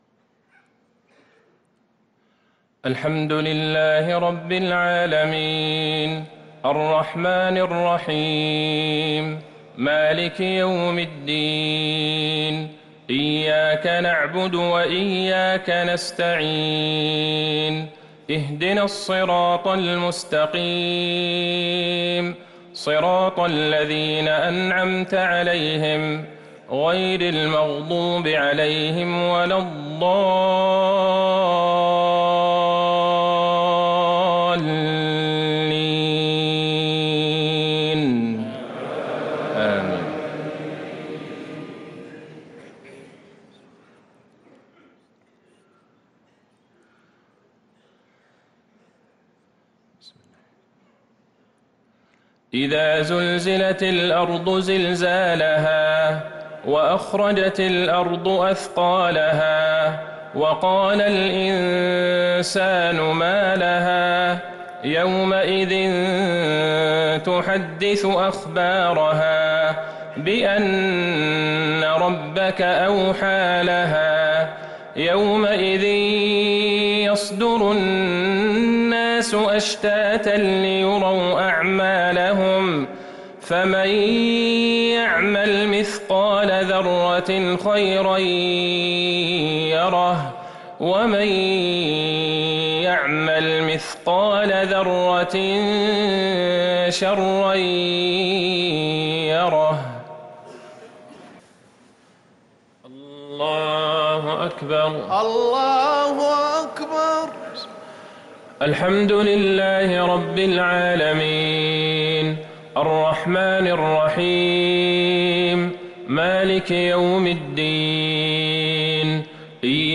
صلاة المغرب للقارئ عبدالله البعيجان 13 جمادي الأول 1444 هـ